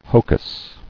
[ho·cus]